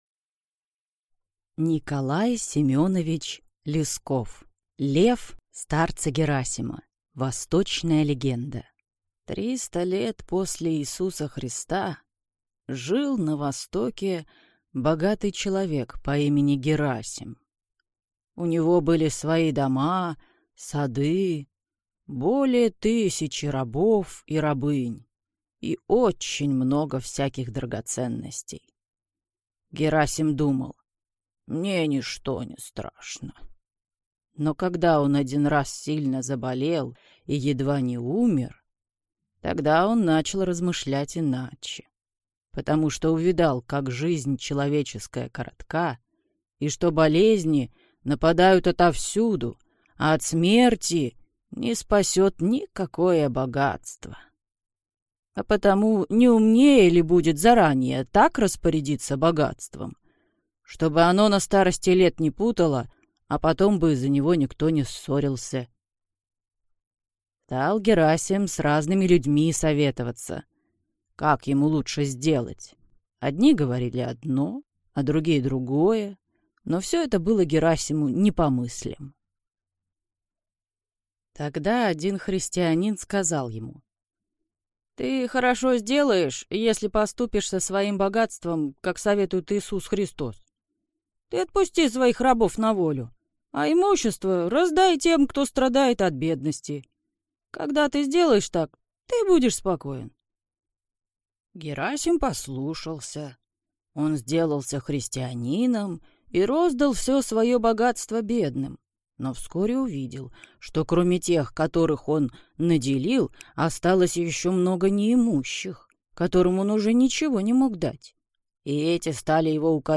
Аудиокнига Лев старца Герасима | Библиотека аудиокниг